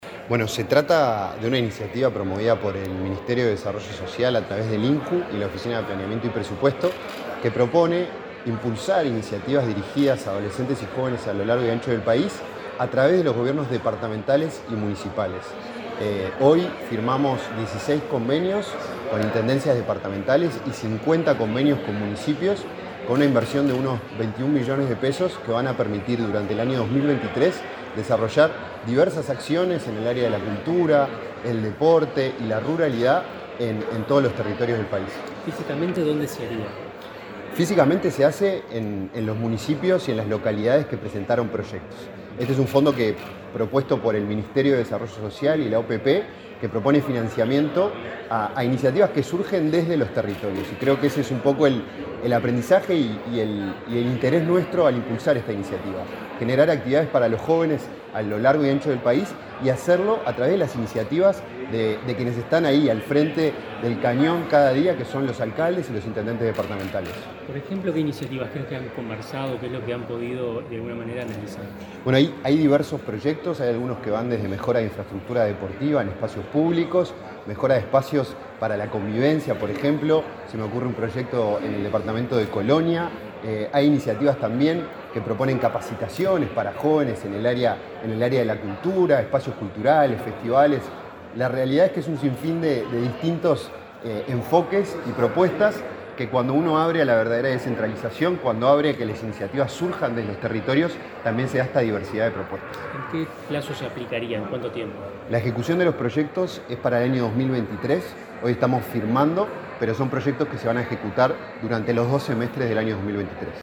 Declaraciones a la prensa del director del INJU, Felipe Paullier
El director del Instituto Nacional de la Juventud (INJU), Felipe Paullier, dialogó con la prensa antes de participar en la firma de un convenio entre